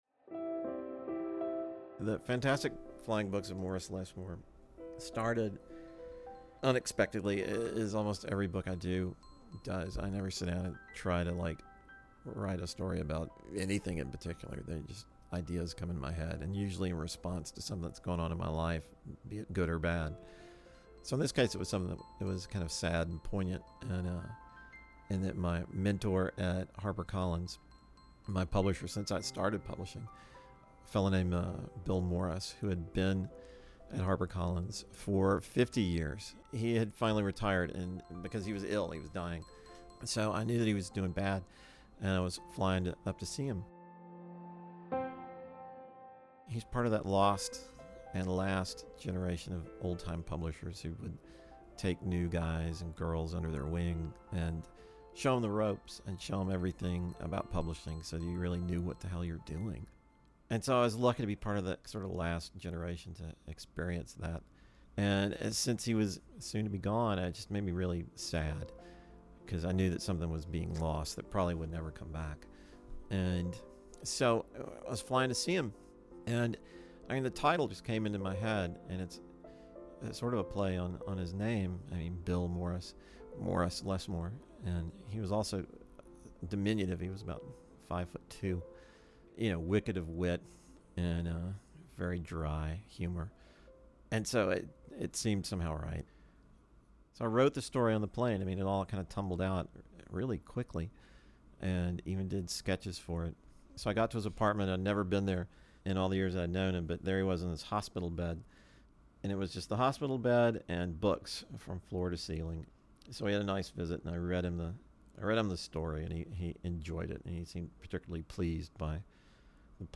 Illustrator and writer William Joyce discusses the heartwarming origin story behind his book and Oscar-winning short film, The Fantastic Flying Books of Mr. Morris Lessmore.